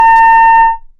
defeat.wav